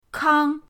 kang1.mp3